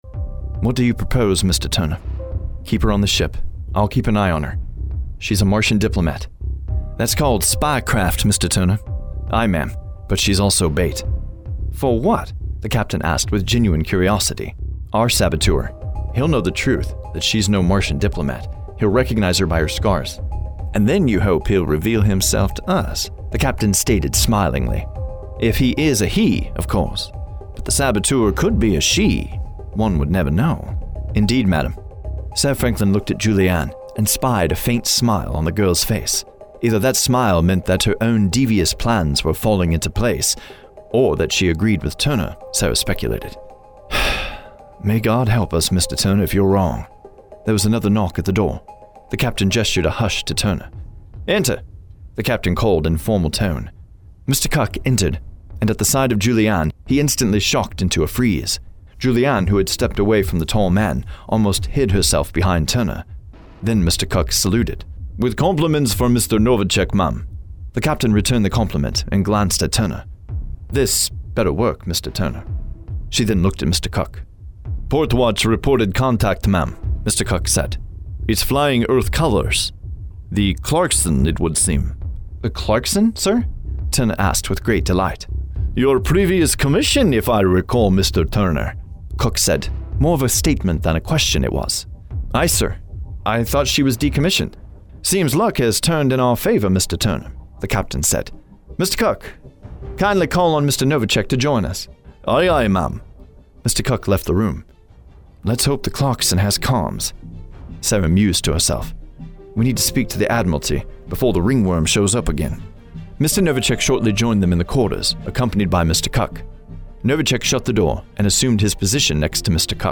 Audiobook - Diplomat of Mars
Middle Aged
Versatile , Unique , Relaxed , the guy next door , energetic , Intuitive , Adaptable , Expressive , Dynamic , Quick turn around , Takes direction very well!